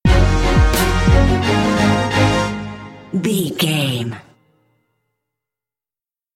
Fast paced
In-crescendo
Aeolian/Minor
B♭
strings
drums
horns